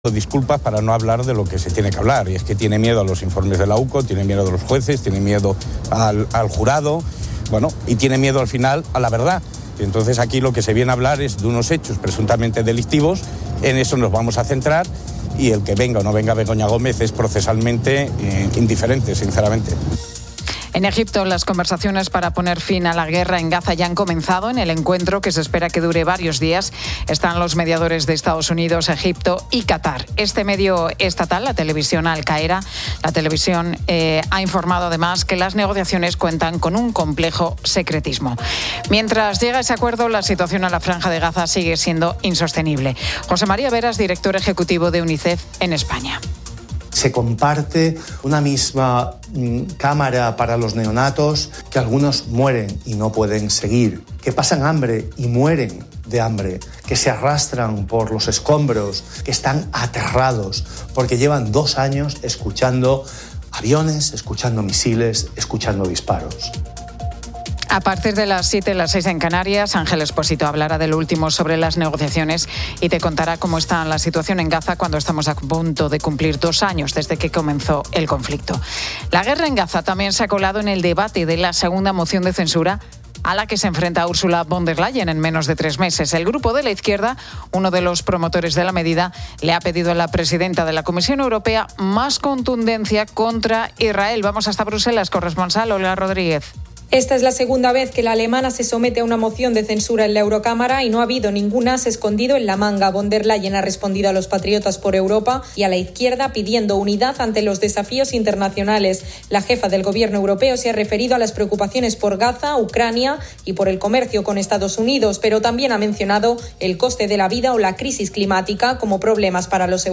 La Tarde 18:00H | 06 OCT 2025 | La Tarde Pilar García Muñiz presenta la historia de cinco mujeres que, tras superar un cáncer, viajaron a la Antártida para demostrar que hay vida después de la enfermedad.